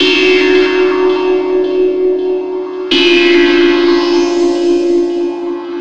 03 Ed Wood 165 Gb.wav